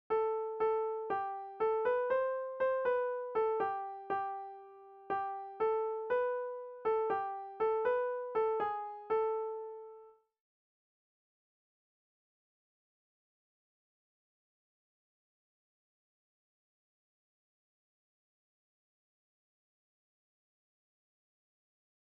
Soprani